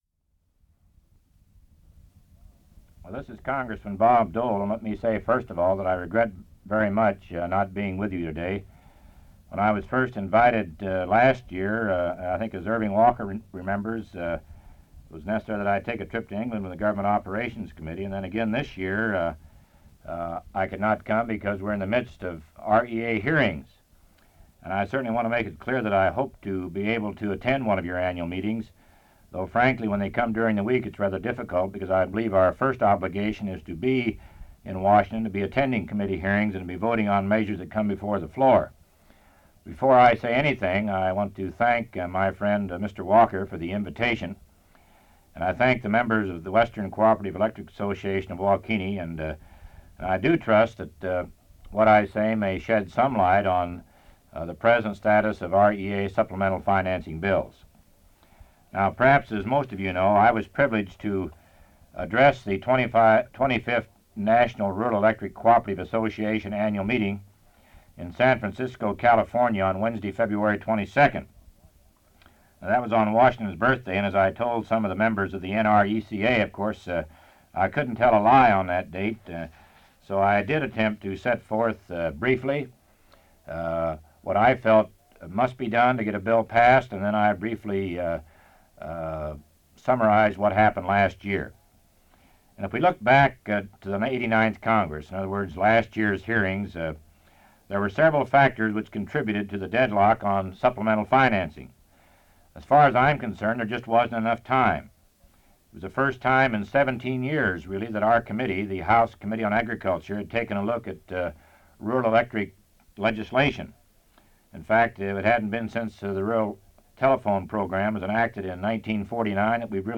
Part of Speech by Representative Dole to the Kansas Electric Cooperative